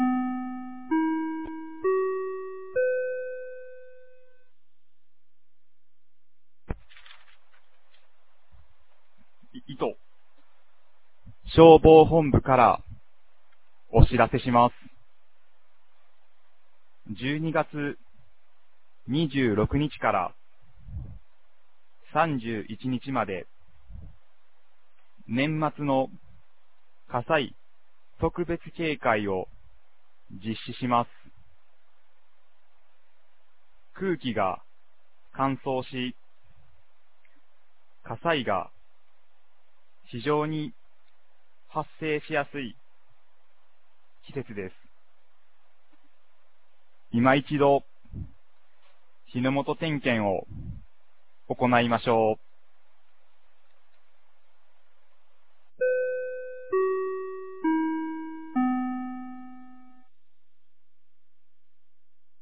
2024年12月16日 10時01分に、九度山町より全地区へ放送がありました。